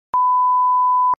5. Пип слегка уникализированный